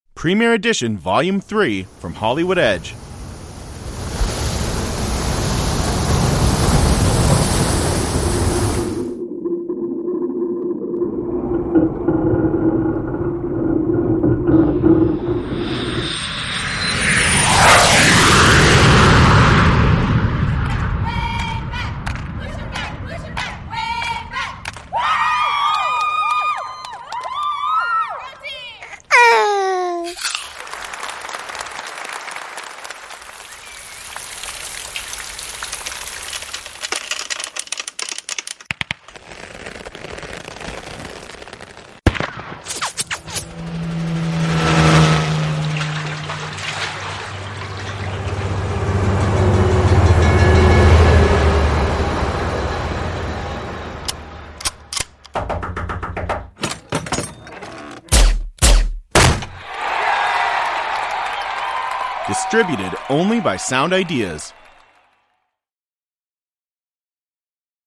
1883个免版税的声音效果
数字立体声录制
35.Wet Movement,Bubbles,Squeak,Impact,Crash,Pops
36.Air,Steam,Hiss,Whooshes,Industry Machines,Hand Tools